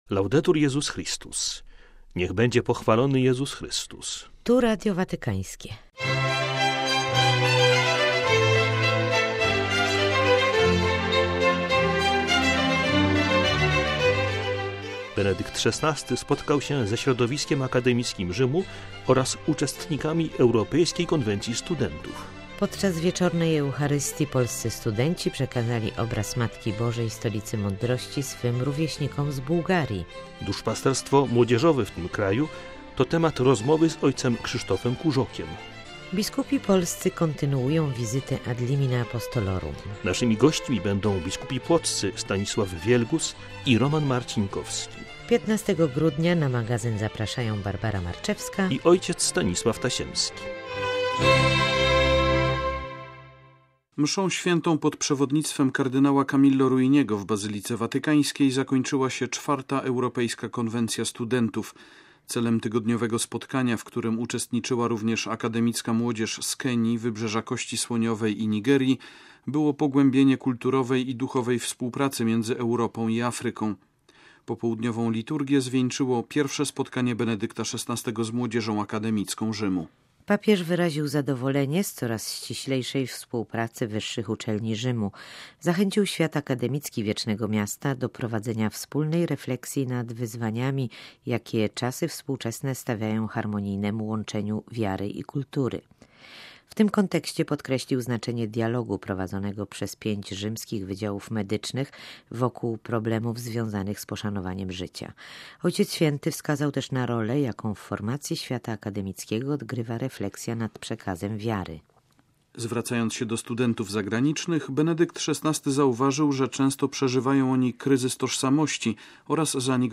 Cap. o duszpasterstwie młodzieżowym w tym kraju Przy okazji wizyty „Ad limina Apostolorum” o diecezji płockiej mówią biskupi Stanisław Wielgus i Roman Marcinkowski Posłuchaj: